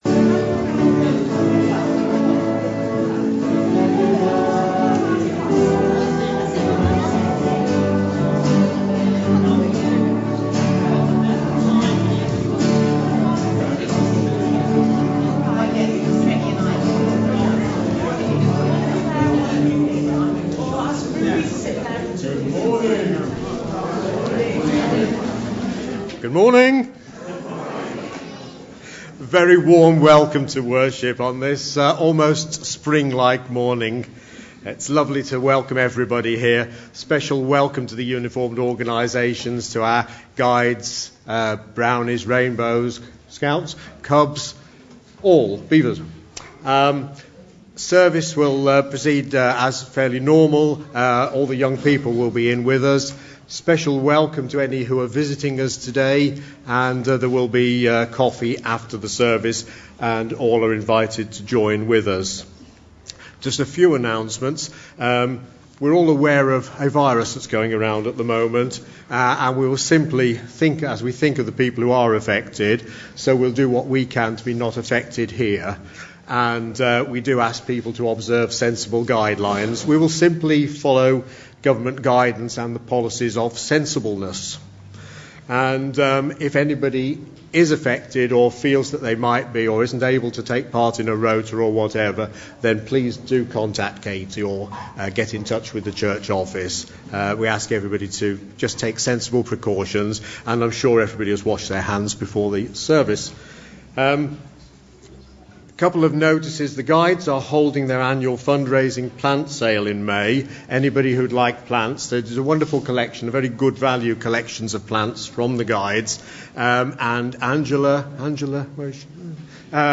2020-03-08 Morning Worship
Genre: Speech.